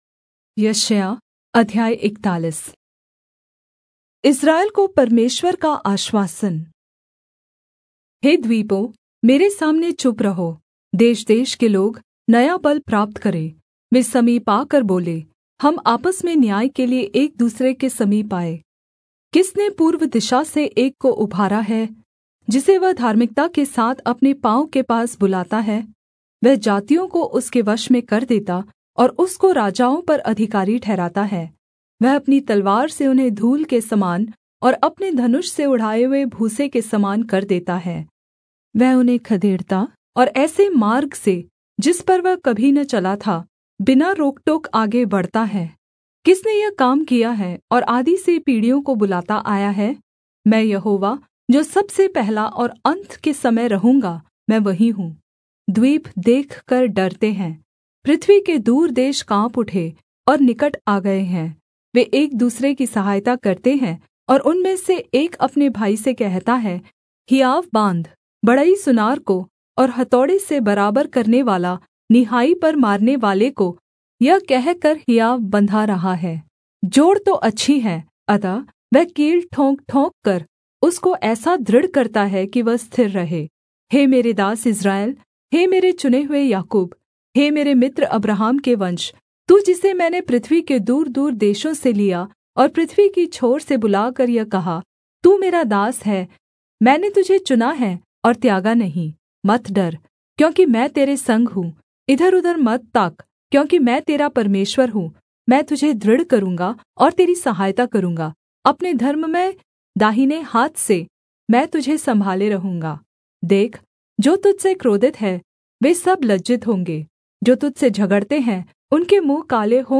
Hindi Audio Bible - Isaiah 2 in Irvhi bible version